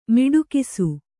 ♪ miḍukisu